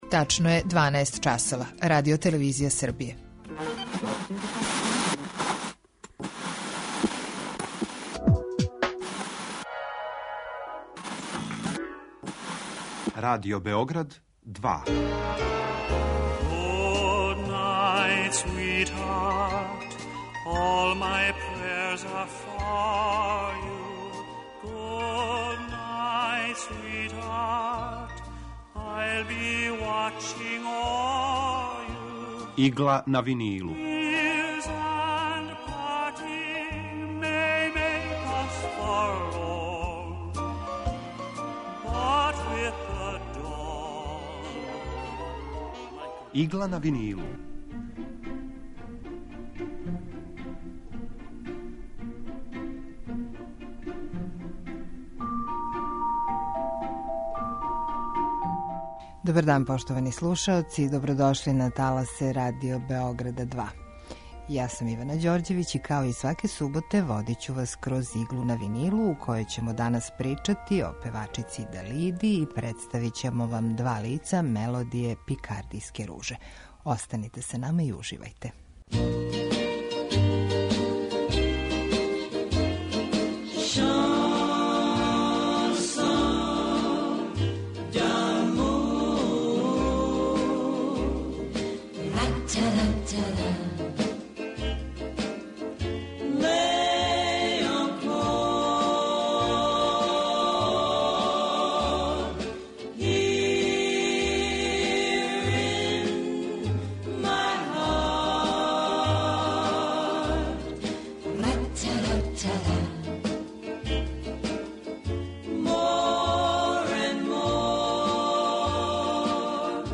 Евергрин музика
У Игли на винилу представљамо одабране композиције евергрин музике од краја 40-их до краја 70-их година 20. века.